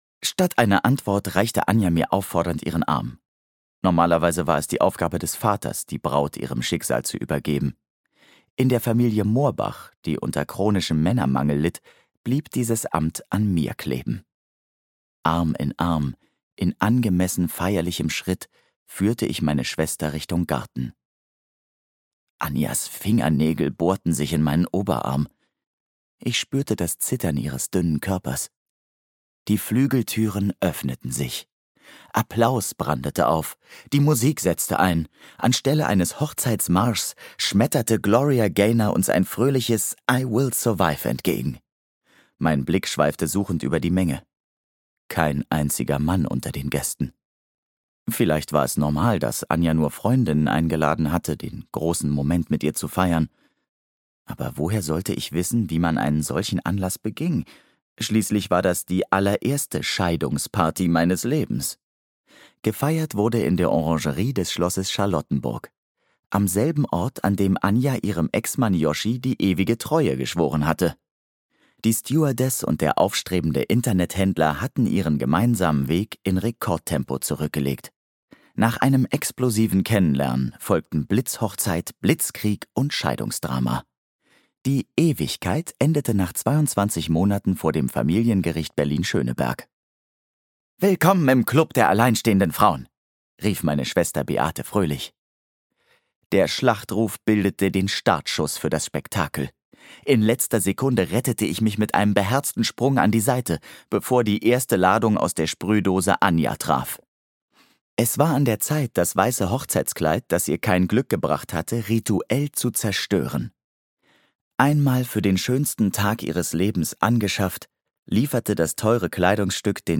Ausgerechnet wir - Monika Peetz - Hörbuch